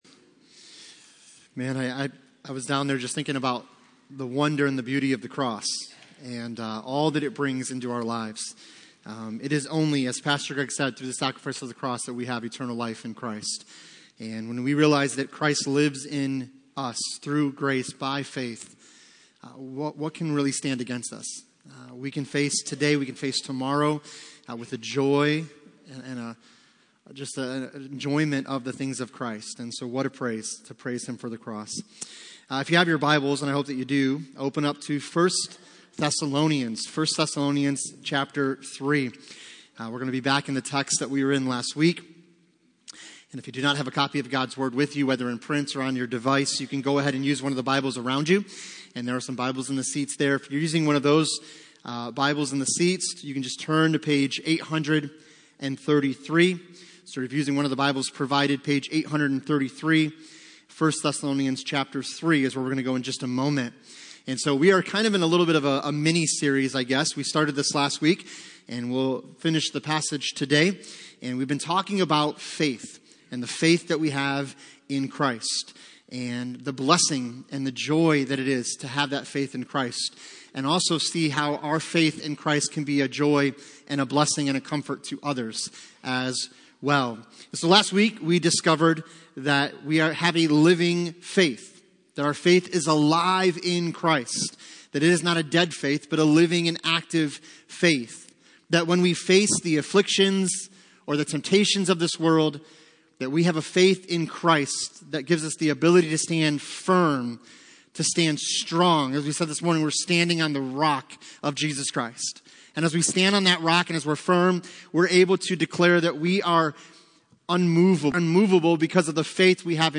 Passage: 1 Thessalonians 3:1-13 Service Type: Sunday Morning